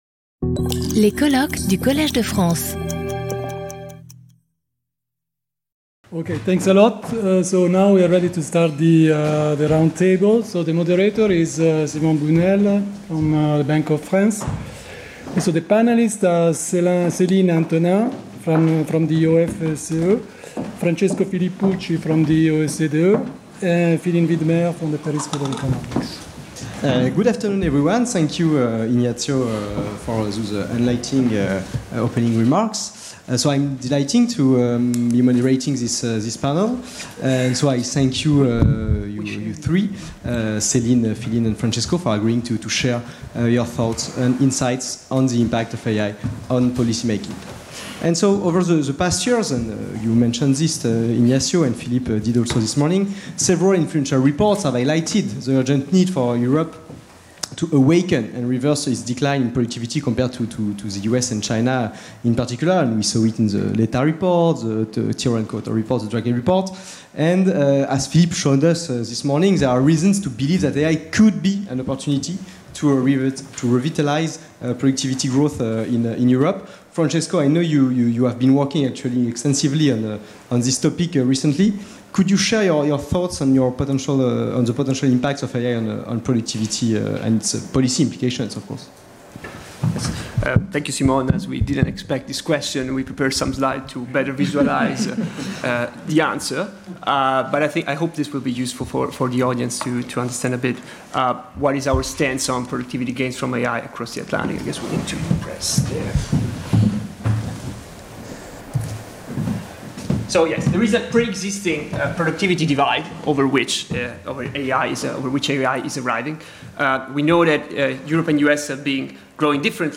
Roundtable: The impact of AI for policy making | Collège de France